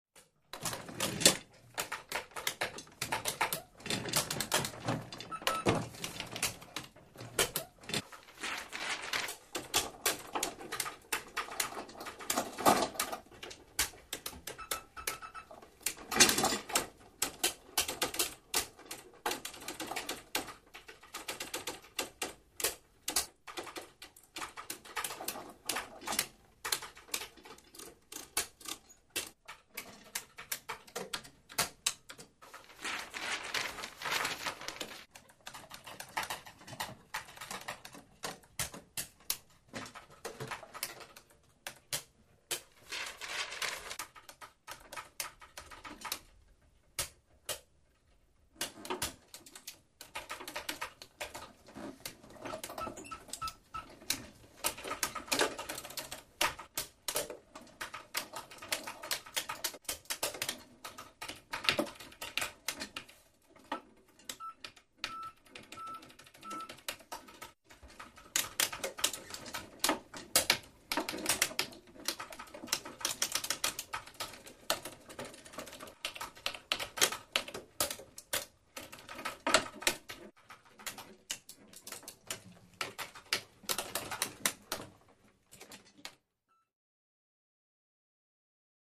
Office Background: Typing And Paper Movement.